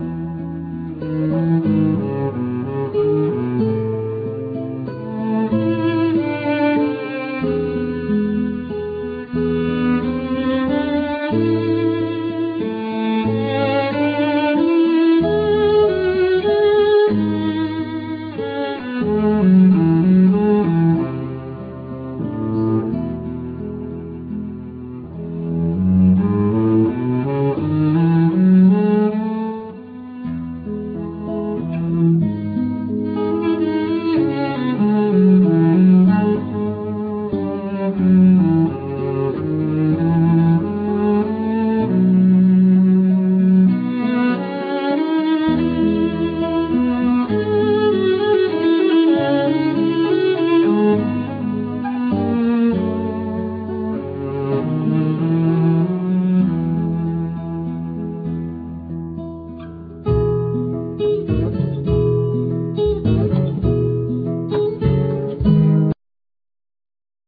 Guitar,Highland pipes,Bodhran
Cello
Djemba,Cajon peruano,Caja
Vocals
Recitado
Pandereta